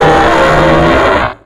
Cri de Brouhabam dans Pokémon X et Y.